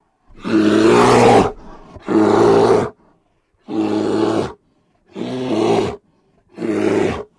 Kodiak Bear Roars